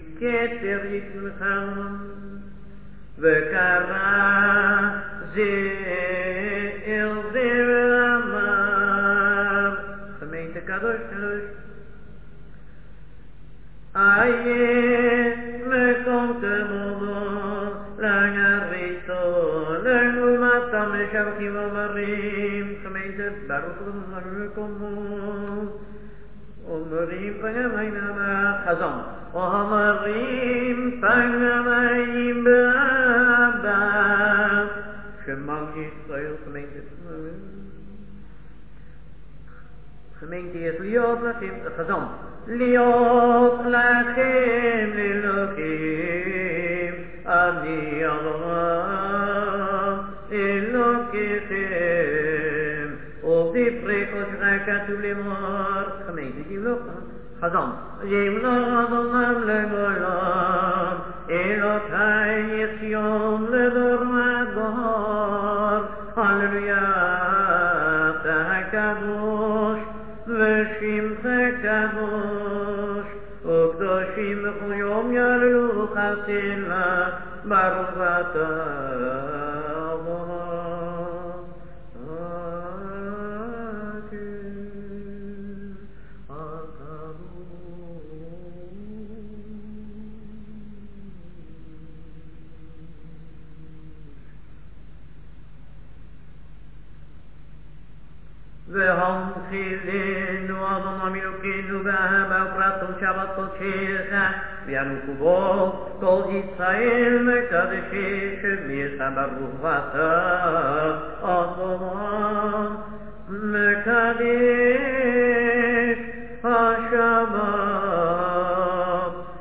Kedusha is said aloud
Melody Rosh Chodes